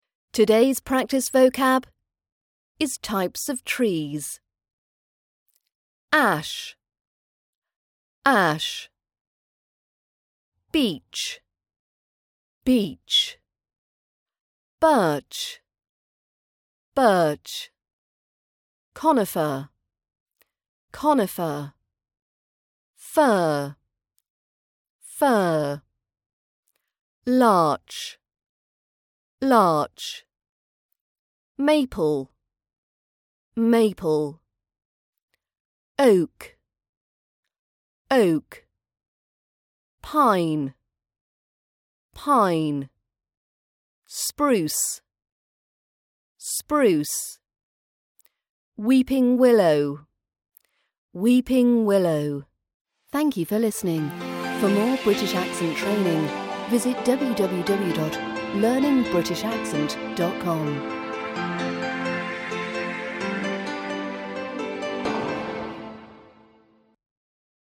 British Accent Training - Vocab practice